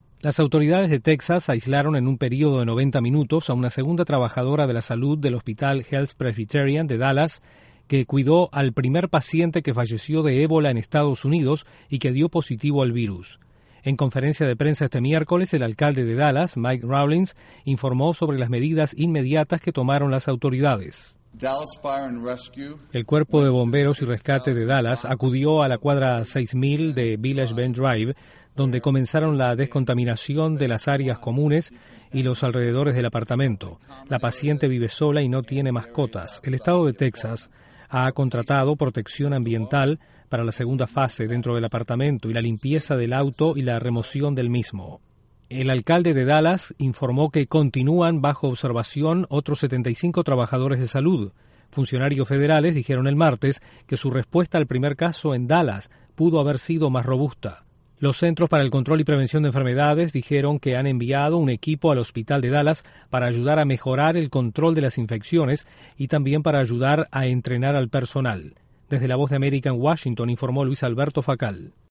Las autoridades de un hospital de Texas confirman el segundo caso de ébola que se registra en Estados Unidos. Desde la Voz de América en Washington informa